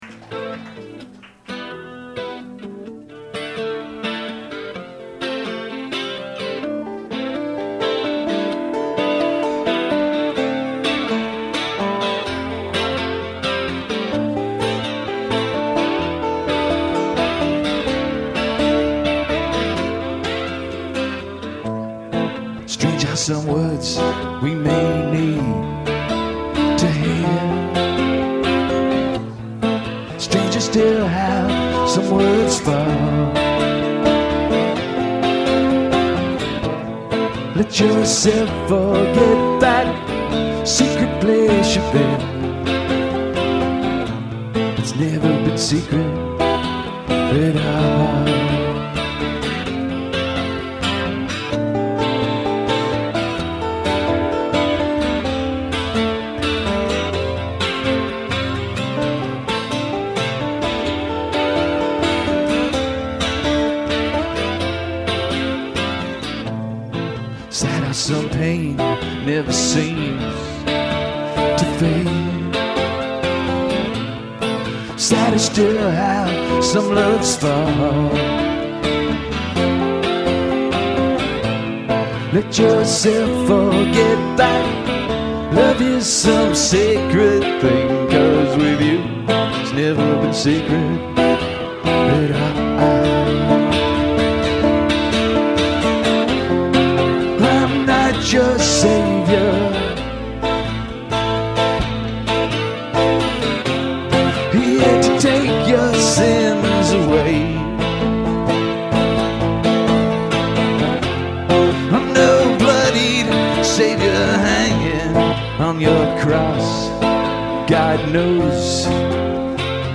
recorded at Tin Angel in Philadelphia
acoustic version
sat in on bass that night